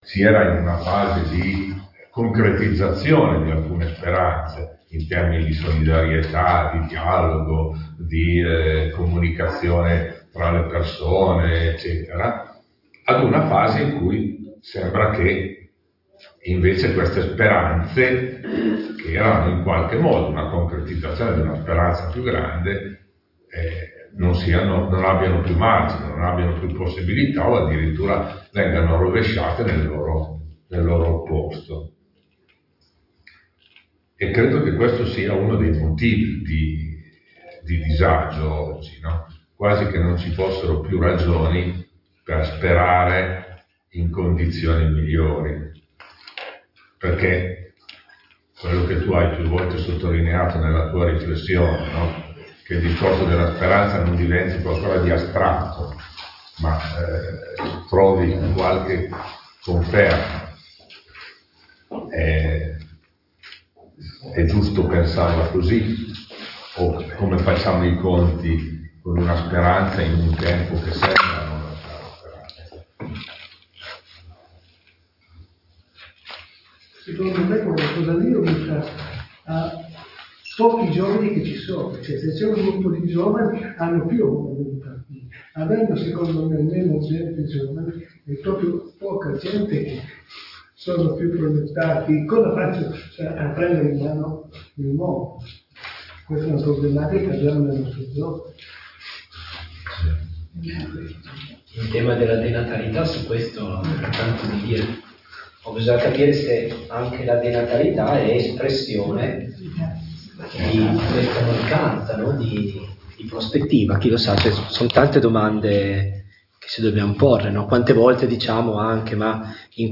Conversazione dopo il primo momento